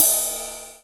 070 - Ride-3.wav